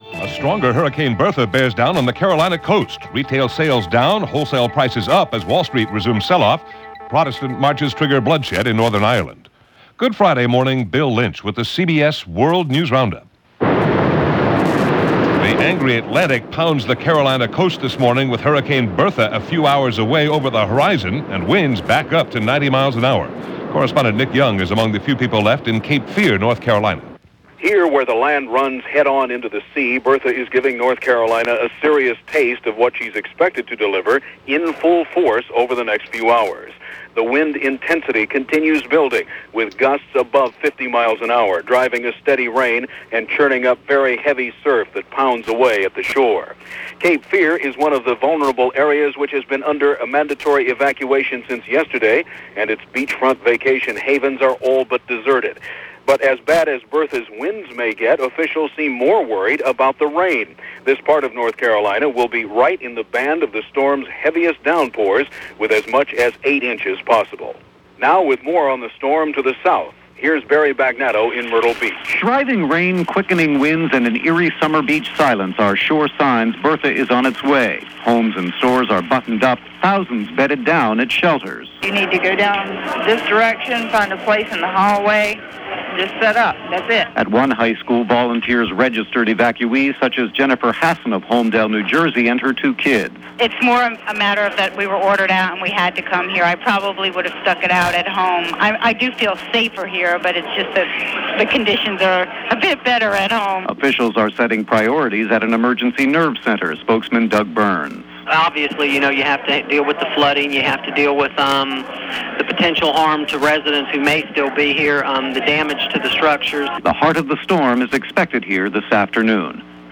And that’s a small slice of what went on, this July 12, 1996 as reported by The CBS World News Roundup.